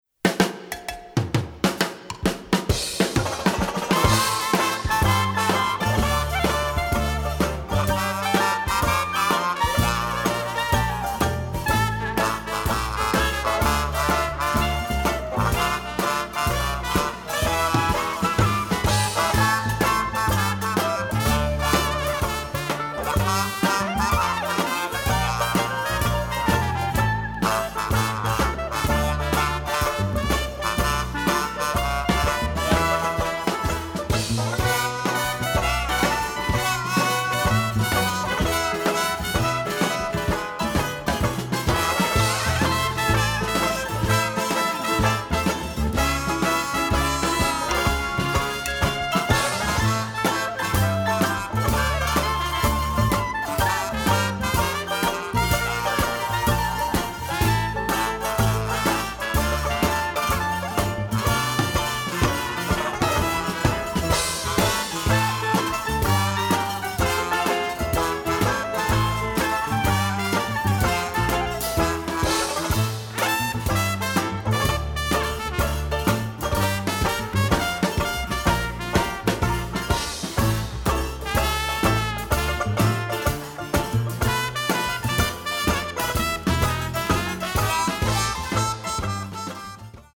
Jazz Instrumental